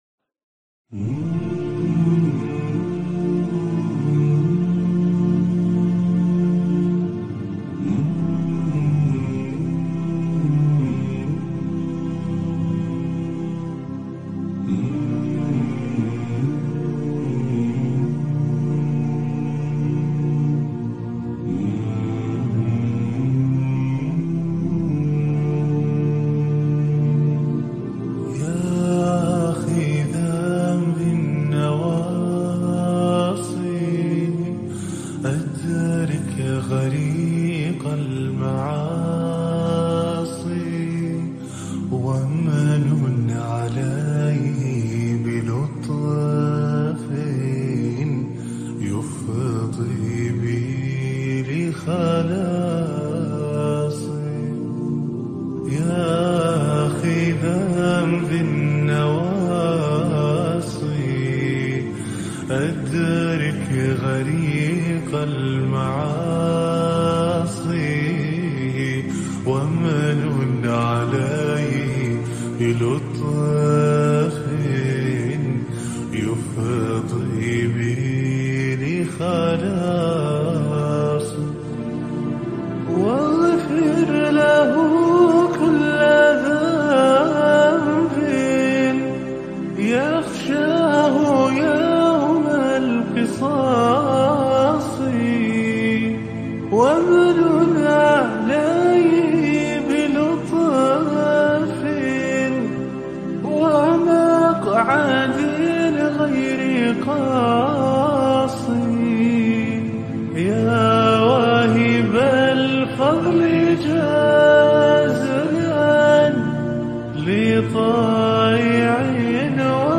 Relax with this beautiful Nasheed.